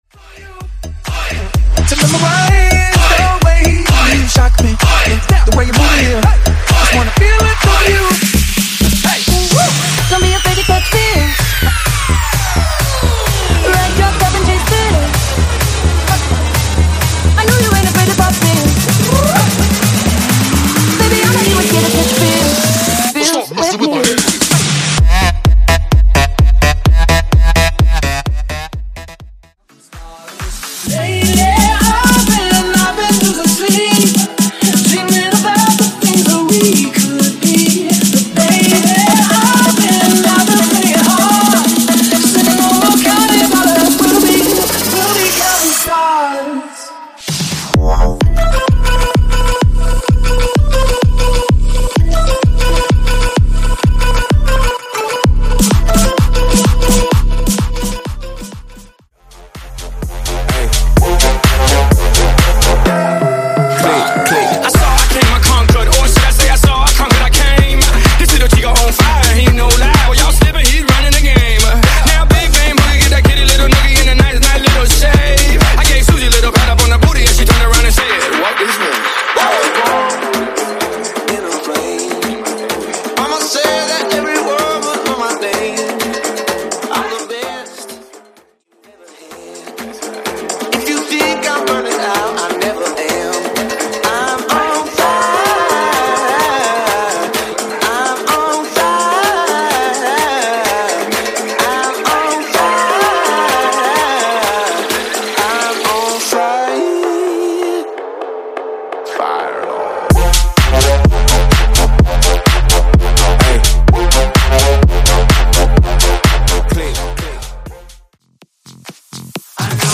Halloween Intro Big Room Edit
Halloween Future House
Halloween Trap Edit
80s Halloween Bounce Redrum) 128 Bpm